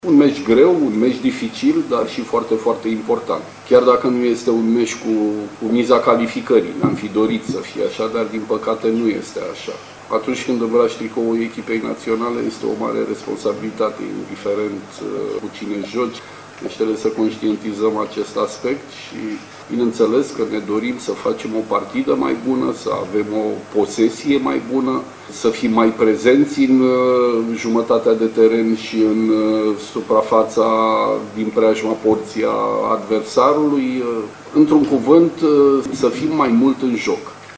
Naționala de fotbal tineret a României dorește să-și reabiliteze imaginea în fața propriilor suporteri la Campionatul European. E ideea pe care a mers selecționerul Emil Săndoi, la declarațiile dinaintea jocului cu Croația, programat mâine seară, de la ora 21,45 pe stadionul Steaua.
Emil Săndoi a vorbit și despre obiectivele pe care le urmărește în partida de mâine seară: